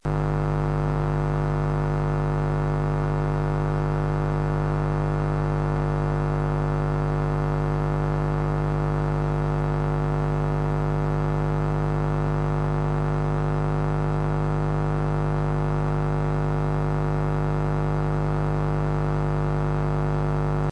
So i put together a little home recording set up with the following gear: 15' Macbook pro (2016) M-audio profire 2626 audio interface Yamaha HS8 studio monitors Everything is working as it should but i just can't seem to get rid of this electrical hum thats on my speakers at all times.
Not simply basic 'hum', but some other crap in there as well.